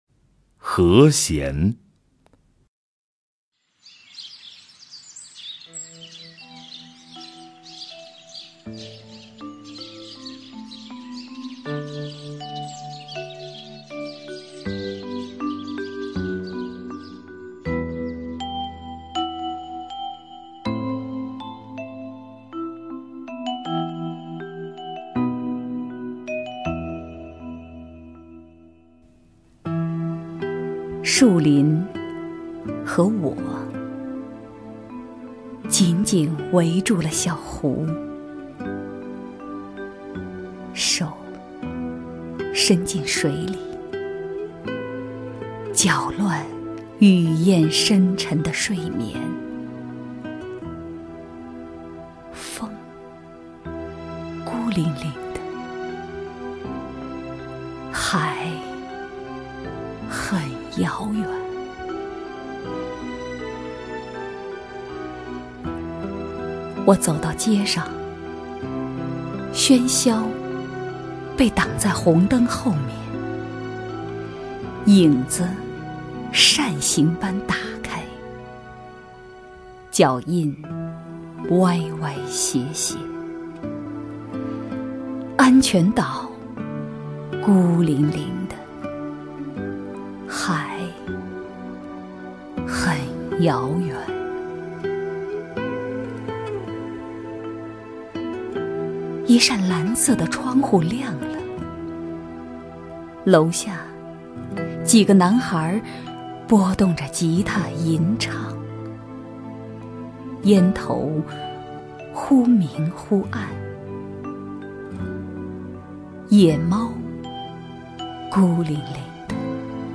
狄菲菲朗诵：《和弦》(北岛)
名家朗诵欣赏 狄菲菲 目录